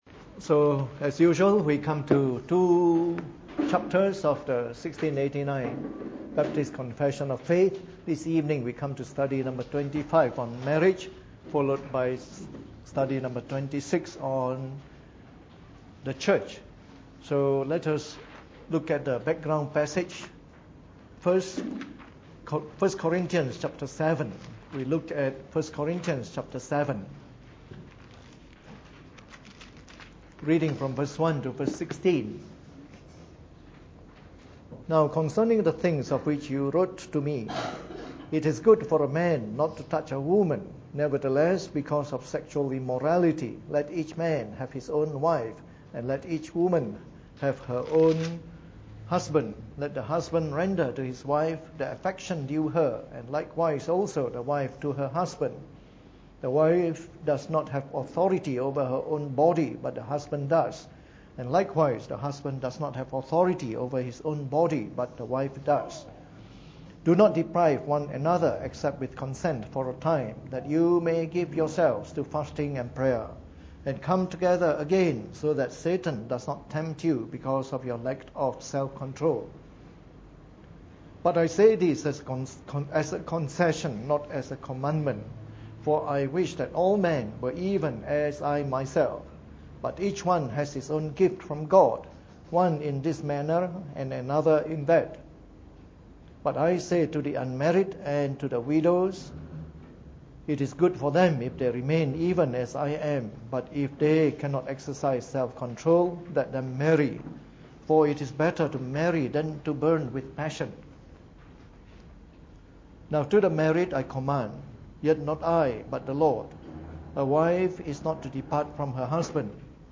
Preached on the 29th of June 2016 during the Bible Study, from our series on the Fundamentals of the Faith (following the 1689 Confession of Faith).